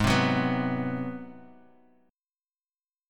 Ab7#9 chord